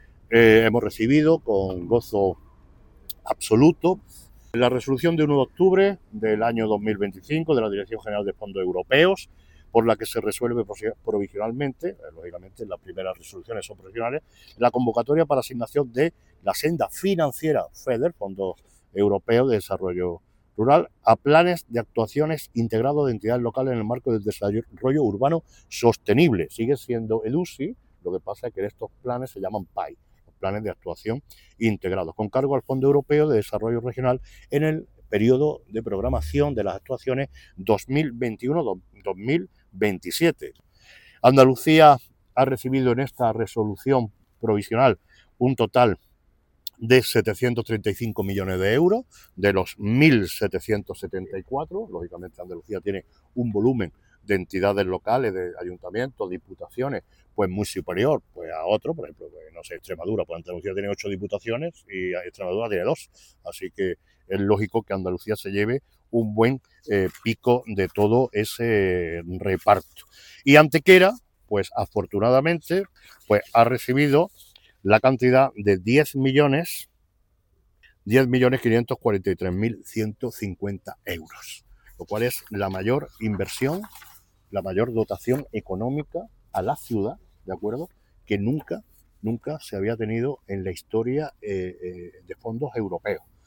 El alcalde de Antequera, Manolo Barón, ha dado a conocer hoy viernes 3 de octubre en rueda de prensa la resolución provisional por la que el Ayuntamiento de Antequera recibirá más de 10 millones de euros de fondos FEDER, en el marco del programa 2021-2027 para Planes de Actuación Integrados (PAI).
Cortes de voz